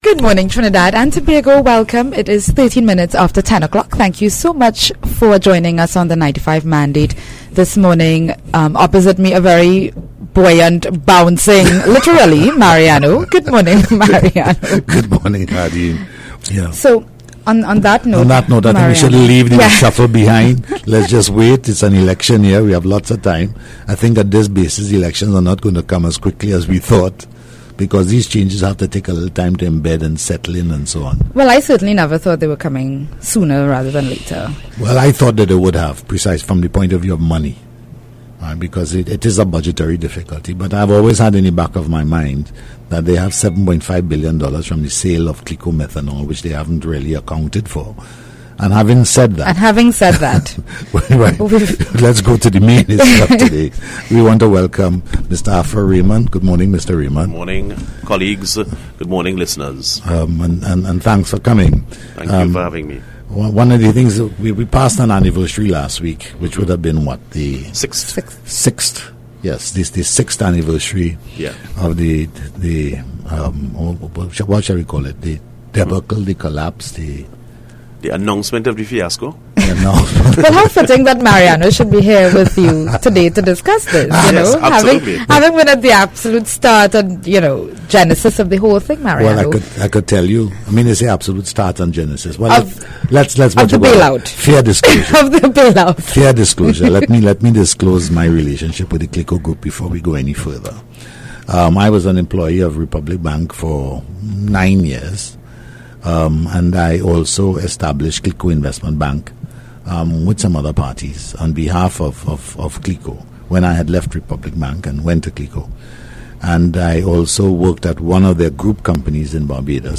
AUDIO: Election Hardtalk interview on Power 102FM – 16 Jul 2015